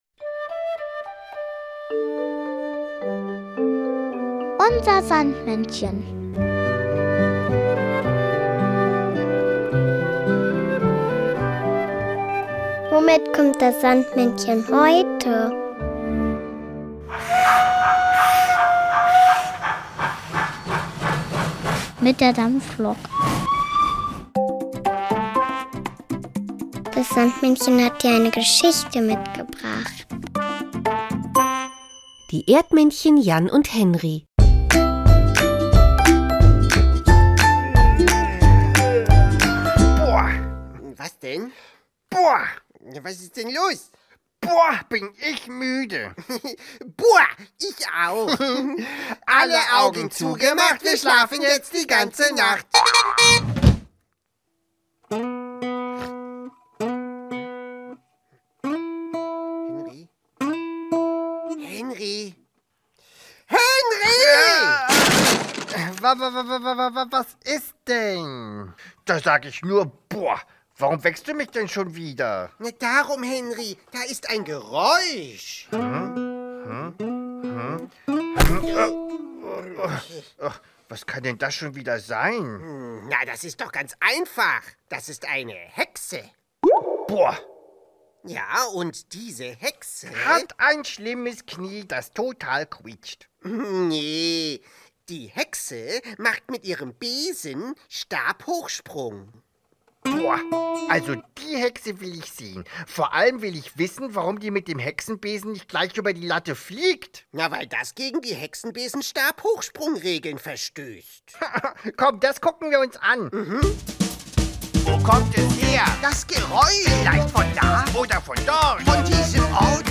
Kinderlied "Traumsong" von Ben Becker.